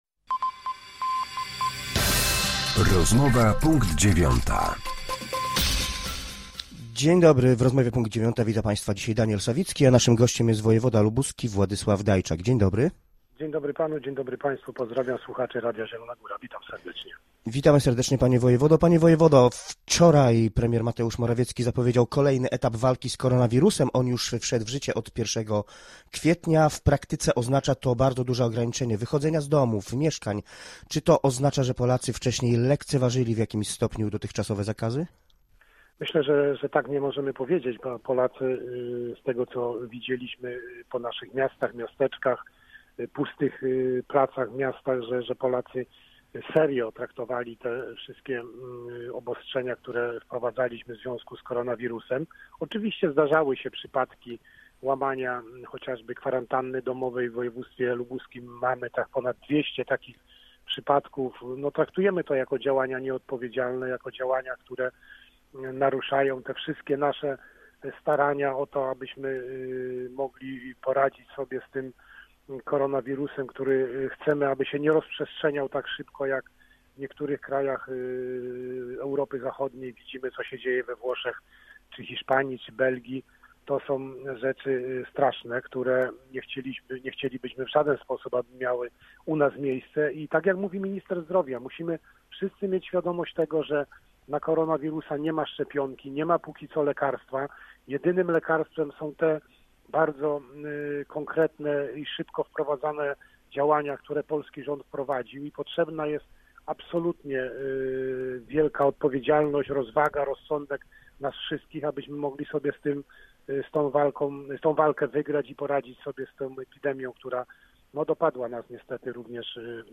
Z wojewodą lubuskim rozmawia